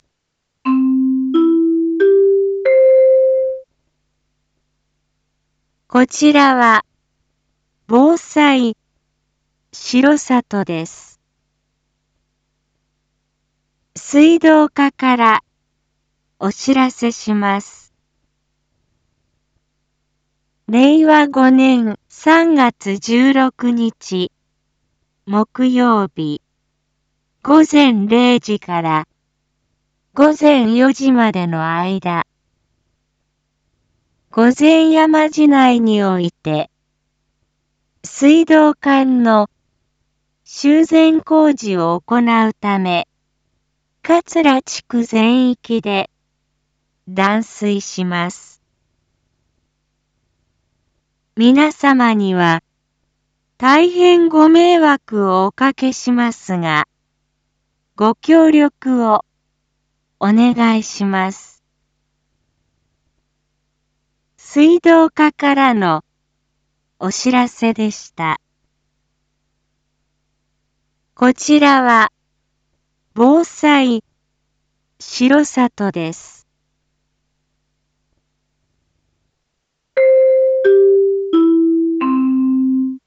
Back Home 一般放送情報 音声放送 再生 一般放送情報 登録日時：2023-03-14 19:06:24 タイトル：水道断水のお知らせ（桂地区限定） インフォメーション：こちらは、防災しろさとです。